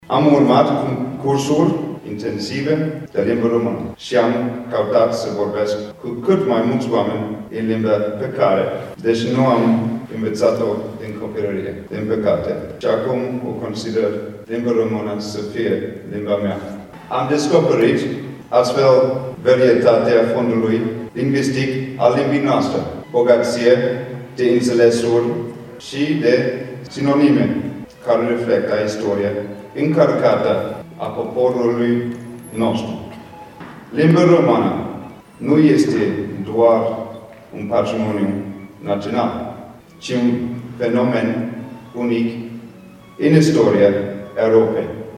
Deschiderea oficială a competiţiei a avut loc aseară, în prezenţa elevilor participanţi, ai profesorilor, ai inspectorilor şcolari şi ai reprezentanţilor autorităţilor locale.
Principele Nicolae le-a vorbit celor prezenţi despre importanţa păstrării limbii române şi le-a urat succes elevilor calificaţi în această fază.